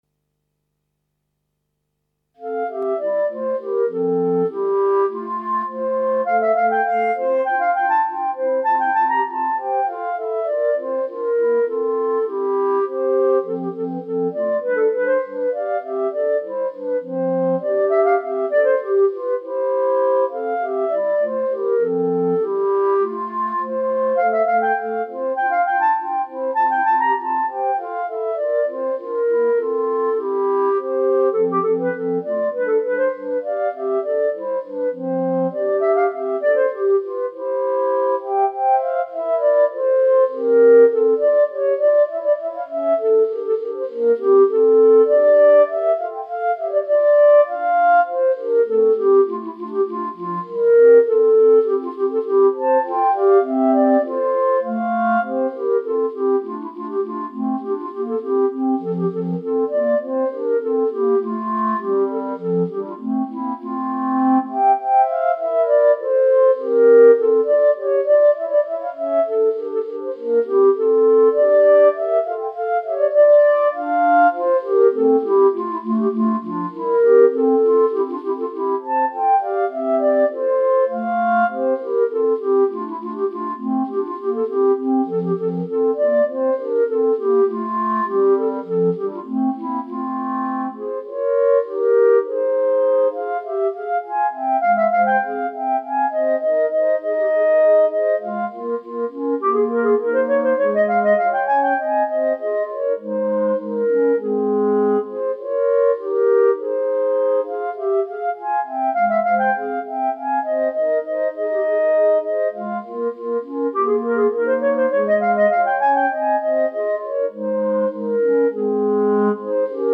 minus Clarinet 3